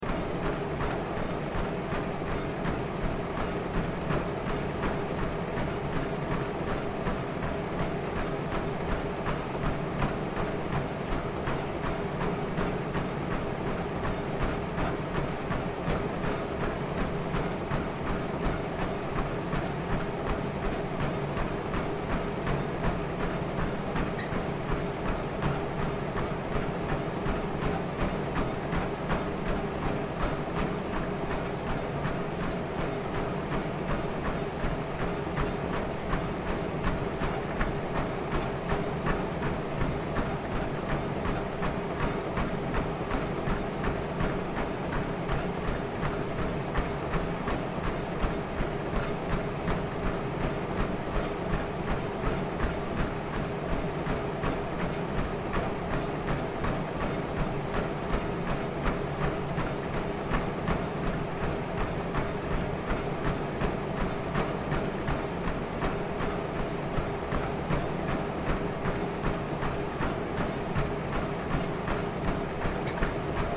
Звуки беговой дорожки
Человек бежит по дорожке с зацикленным звуком